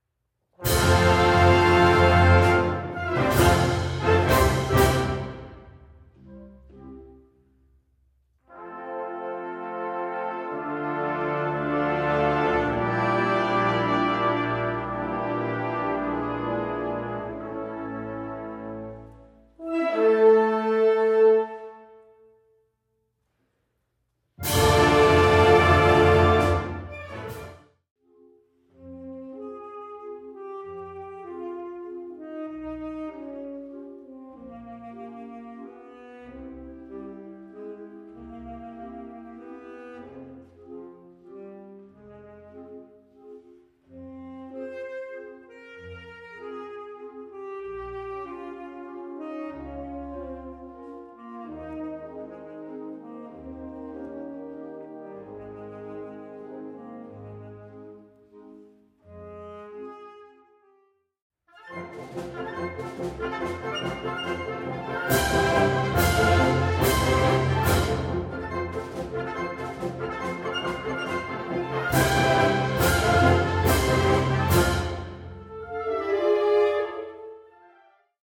F Major（原調）
よりメリハリのある演奏になるように薄い部分を作るなどの工夫を施しました。